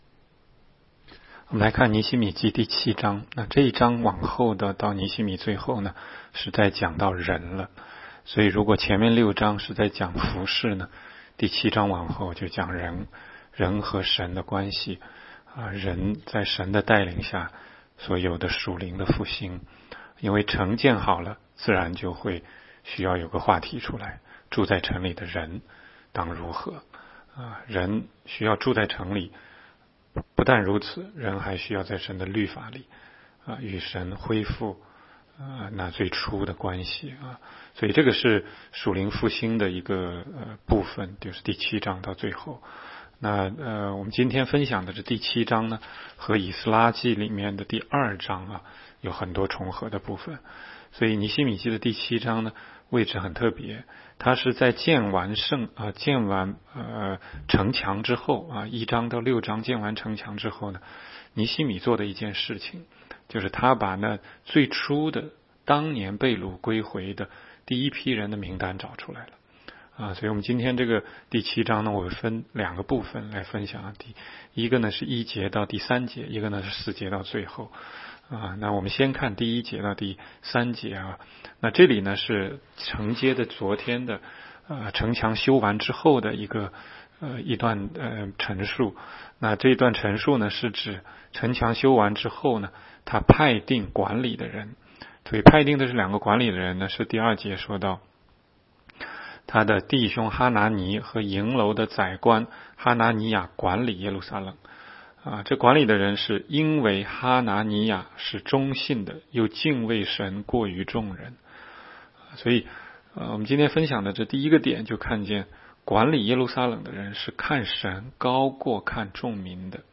16街讲道录音 - 每日读经-《尼希米记》7章